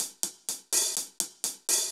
Index of /musicradar/ultimate-hihat-samples/125bpm
UHH_AcoustiHatA_125-02.wav